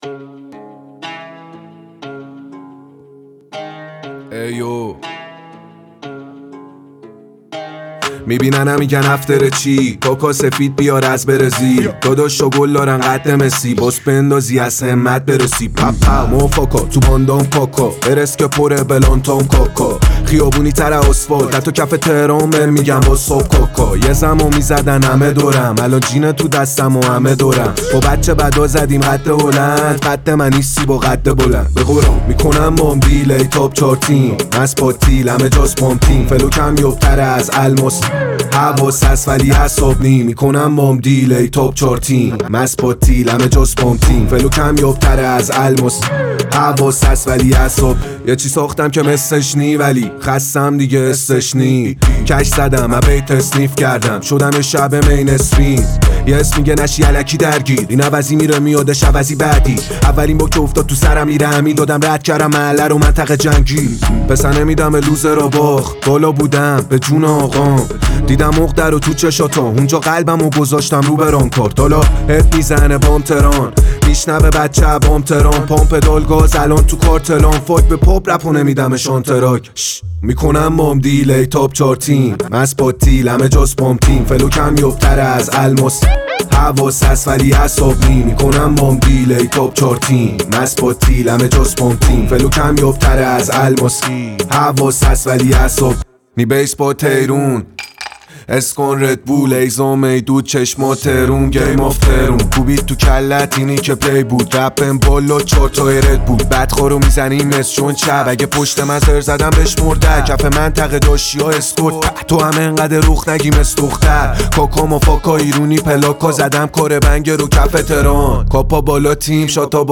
• ژانر: پاپ